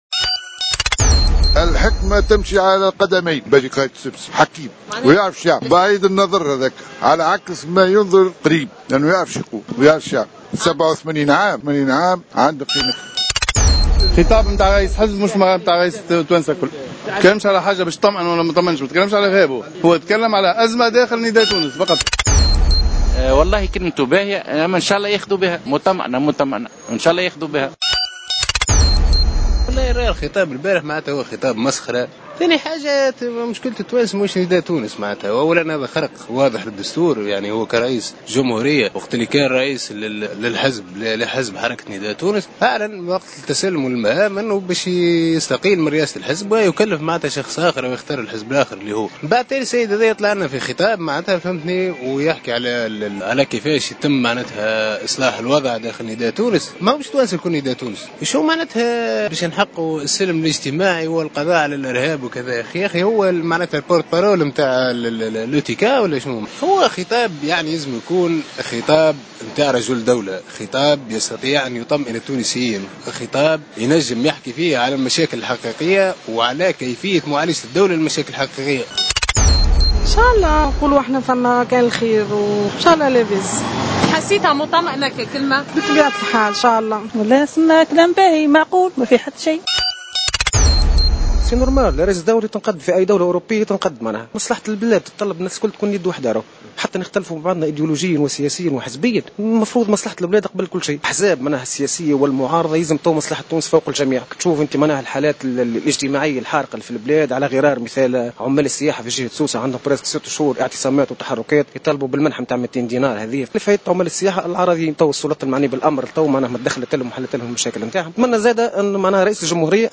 اختلفت آراء عدد من المواطنين تحدّثوا في استجواب أجرته معهم "الجوهرة أف أم" اليوم وسط مدينة سوسة بخصوص خطاب رئيس الجمهورية الباجي قائد السبسي الذي بثتّه مساء أمس الأحد 29 نوفمبر 2015 جل القنوات التلفزية و الإذاعات العمومية و الخاصة.